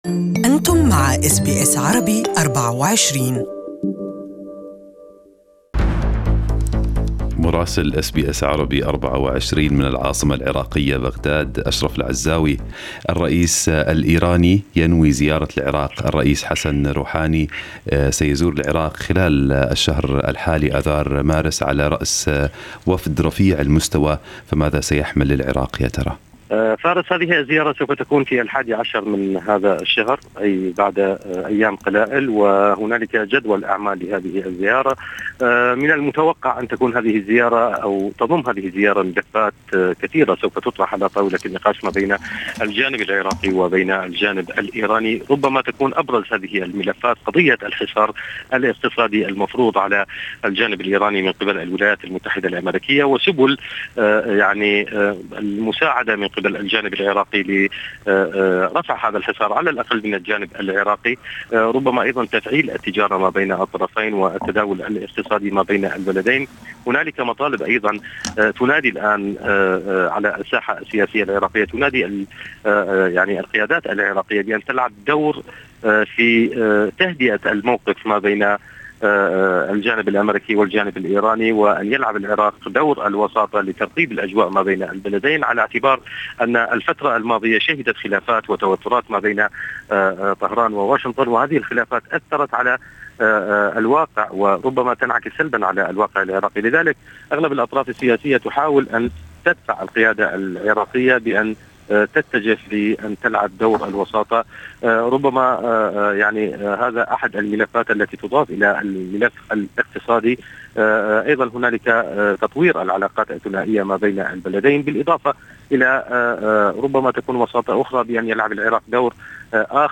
استمعوا إلى تقرير مراسلنا في العراق